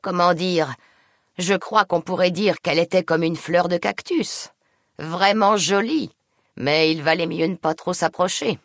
Catégorie:Dialogue audio de Fallout: New Vegas